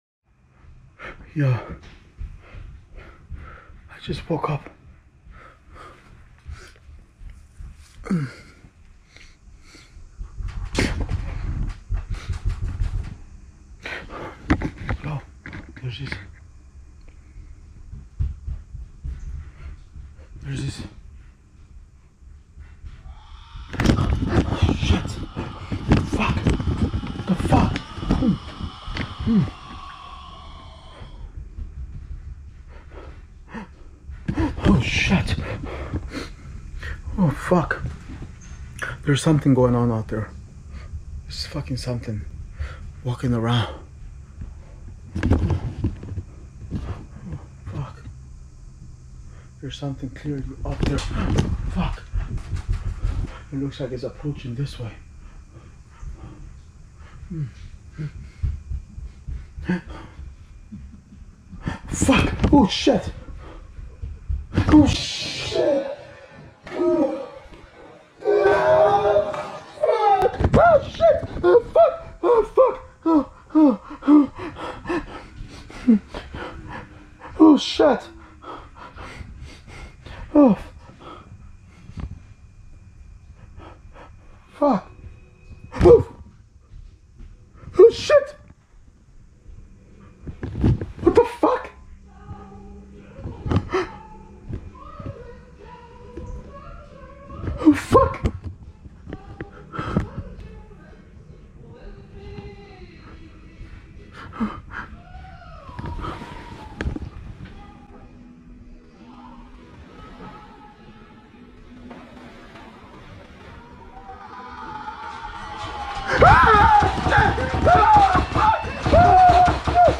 This wasn’t the first time it happened, but it was one of the rare nights I had my GoPro ready.
Above me, in the attic, footsteps pounded—running, growling, even laughing.
They rushed up and down outside my bedroom door, screaming and pounding as if trying to break in.
My door rattled violently, as if something was determined to rip it open. The pounding shook the walls.